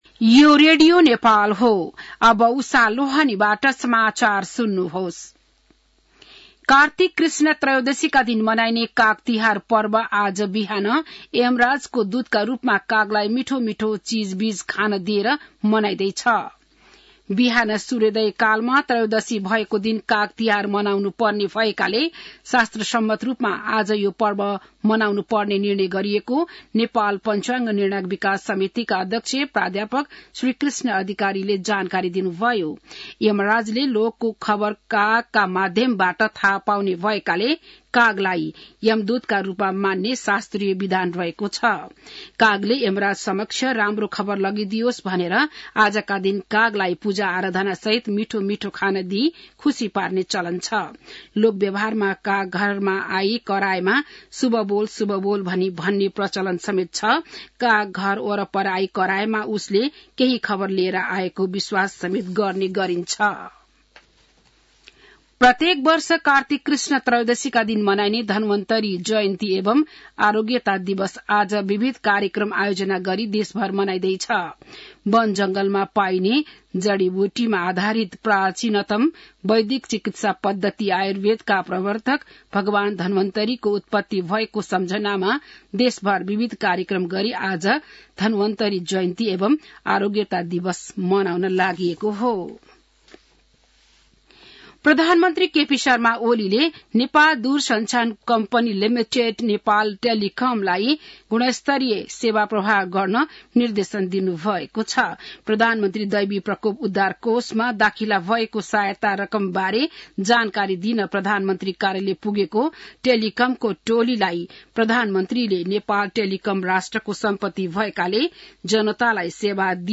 बिहान १० बजेको नेपाली समाचार : १४ कार्तिक , २०८१